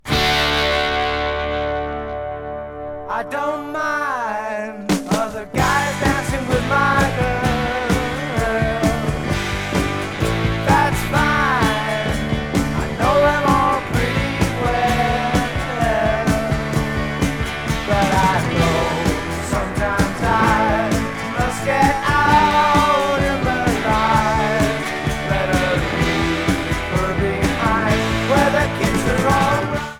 1975 UK LP